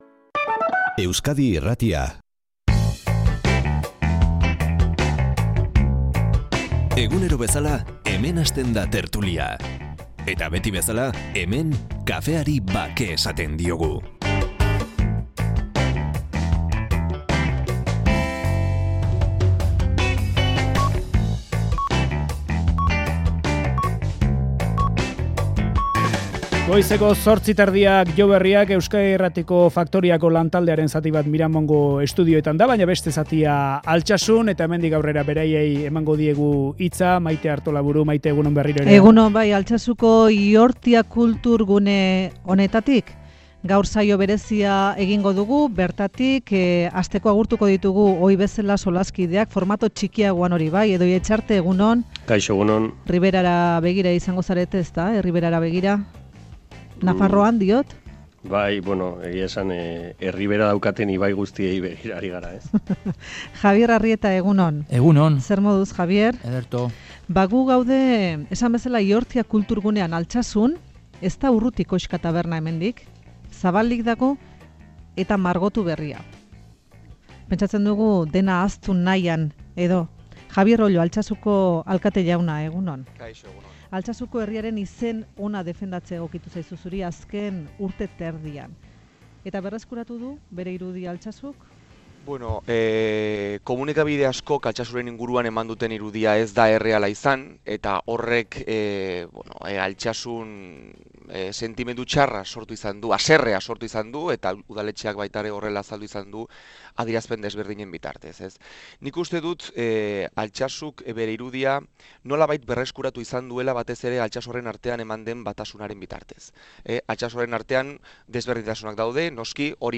Audioa: Faktoria zuzenean Altsasutik. Javier Ollo, Altsasuko alkateari elkarrizketa.